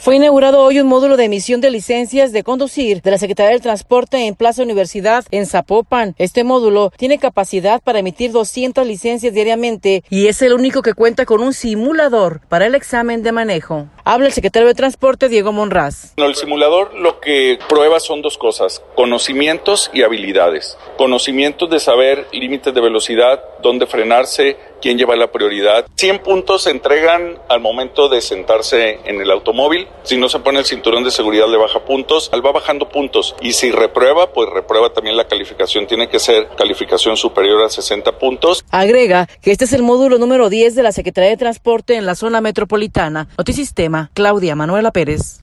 Habla el secretario de Transporte, Diego Monraz.